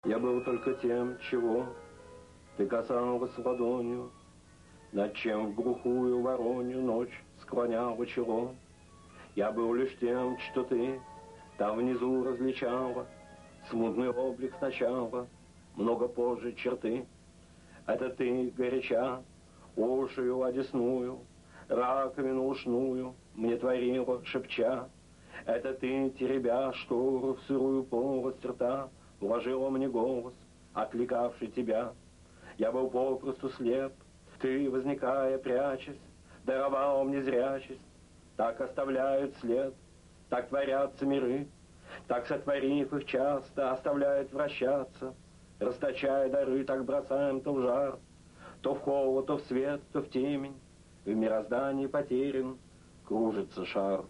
10. «И.Бродский – Я был только тем… (читает автор)» /
Brodskiy-Ya-byl-tolko-tem.-chitaet-avtor-stih-club-ru.mp3